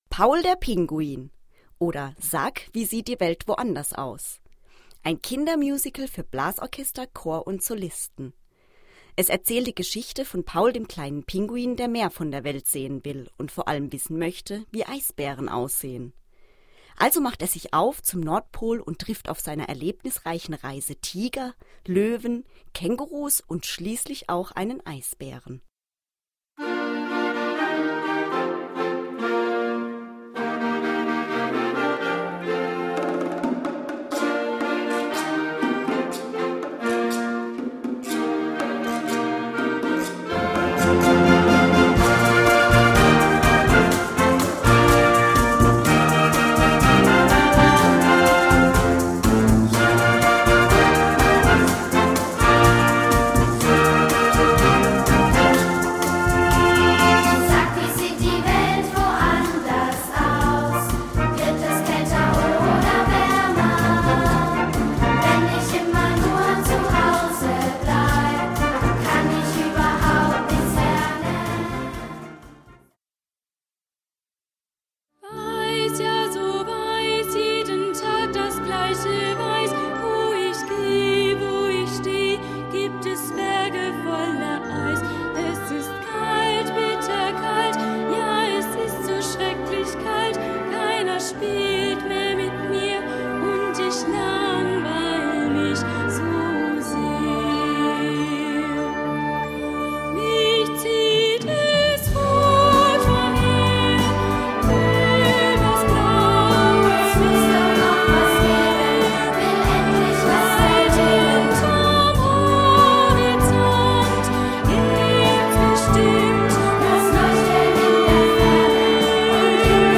Gattung: Kindermusical
Besetzung: Blasorchester